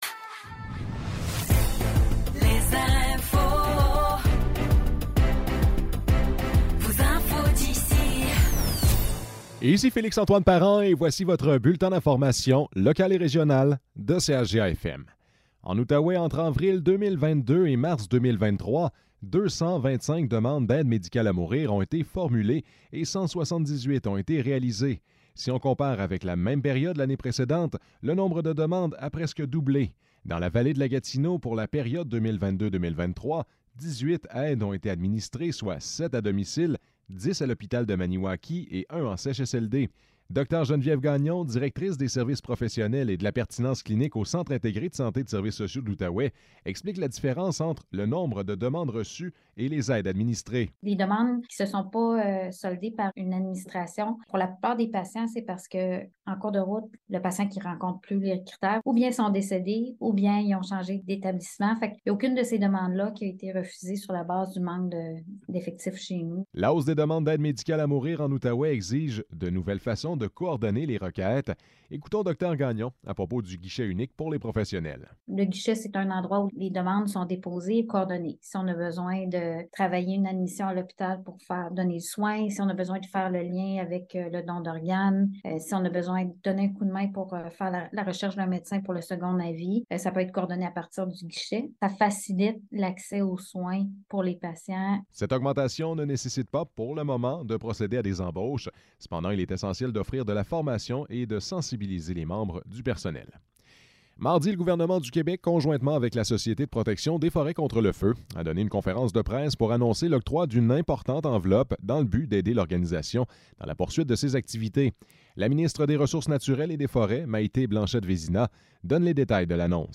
Nouvelles locales - 15 novembre 2023 - 12 h